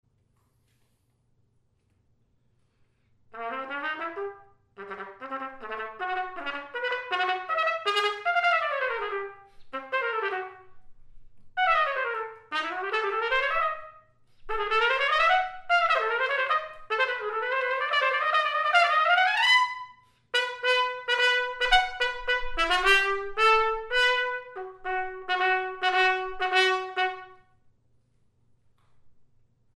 For Bb or C trumpet and piano (1996)
The following clips were recorded on an Eclipse C trumpet.
mvt. 1 cadenza conclusion